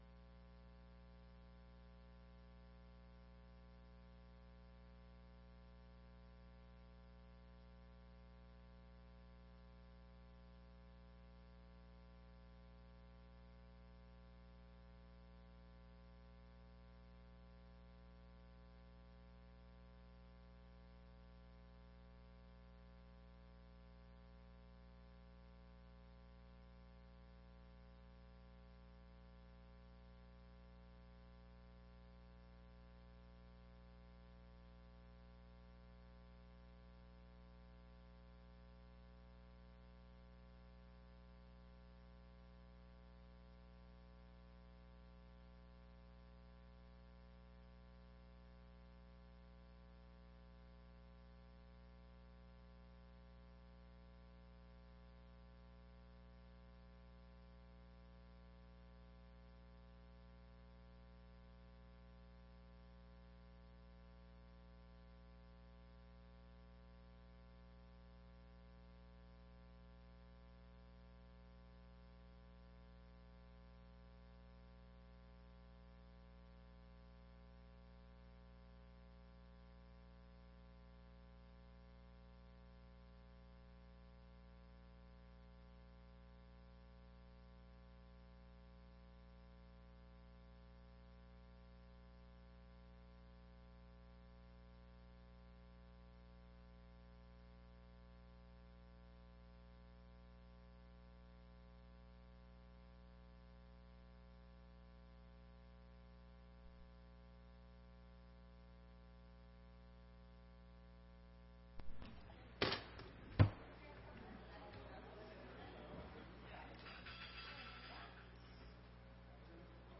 4ª Sessão Ordinária de 2018